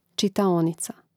čitaònica čitaonica im. ž. (G čitaònicē, DL čitaònici, A čitaònicu, I čitaònicōm; mn.